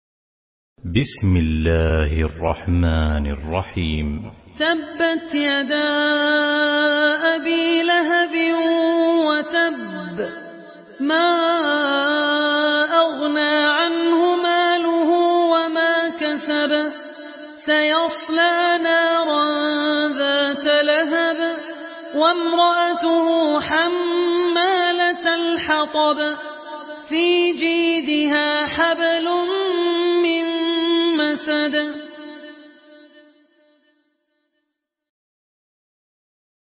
مرتل حفص عن عاصم